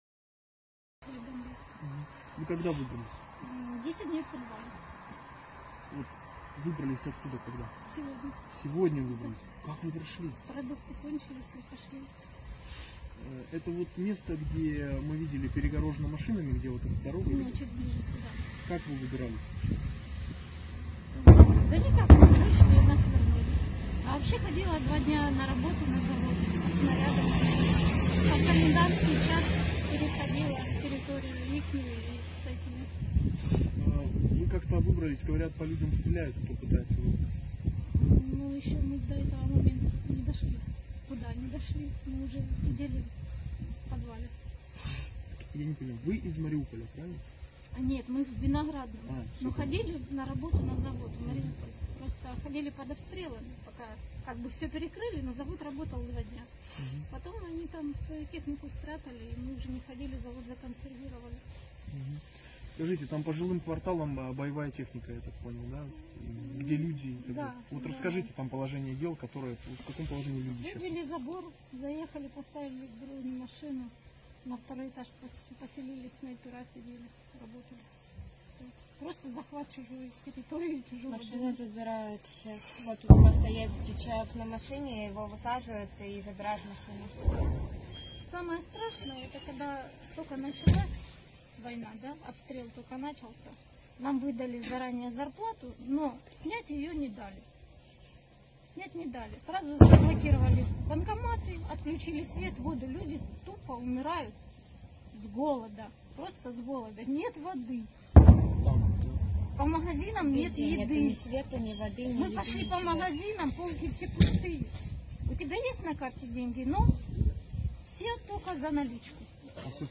Eine der Frauen sagt, sie hat in einer Fabrik in Mariupol gearbeitet.